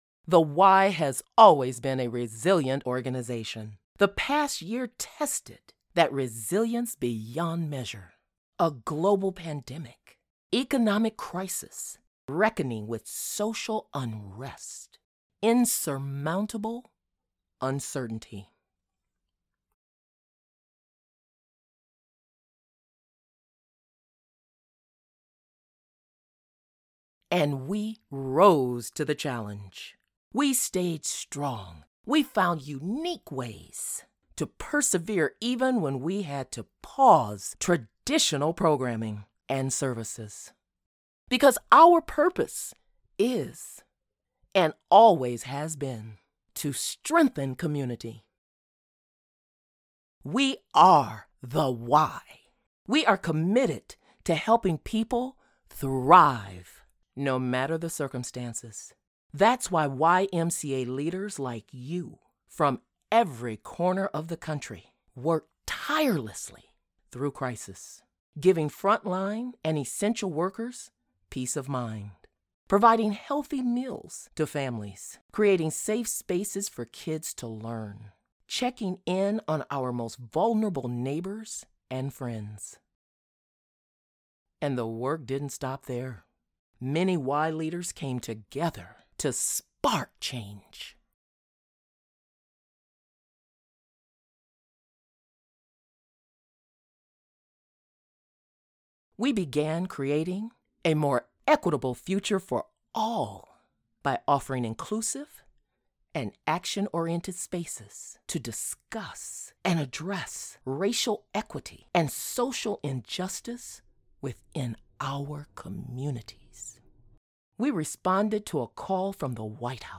Commercial
YMCA Volunteer Leaders Day! I was asked to provide a gritty voice as a way to ignite all in spite of the COVIID despite the emotional impact to the areas, the USA, and the World!
Midwest Dialect